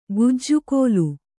♪ gujju kōlu